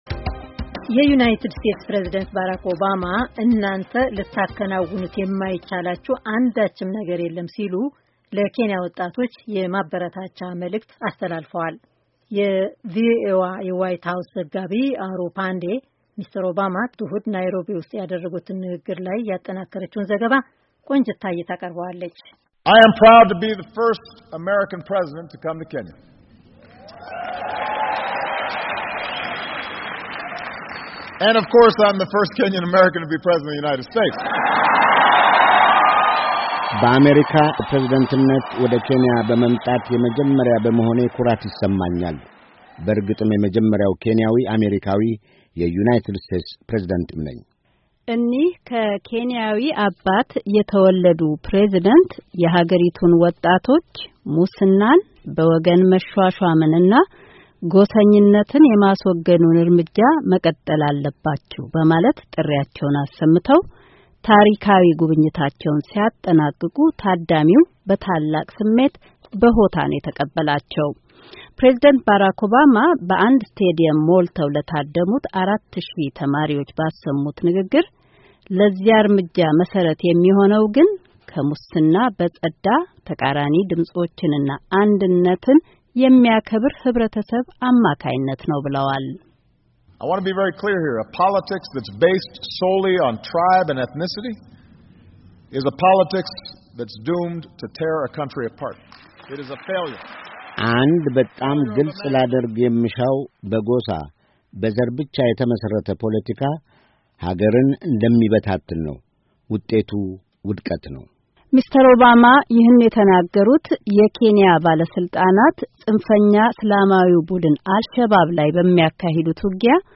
ፕሬዚደንት ኦባማ እሁድ እለት በናይሮቢ ሳፋሪኮም ስቴዲየም ለተሰባሰቡ አራት ሺህ ተማሪዎች ባደረጉት ንግግር “የዚህ ጉዞ መሰረት የሚሆነው ግን ከሙስና የጸዳ፡ ተቃራኒ ድምጾችን እና አንድነትን የሚያከብር ህብረተሰብ ሲኖር ነው” ብለዋል።